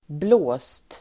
Uttal: [blå:st]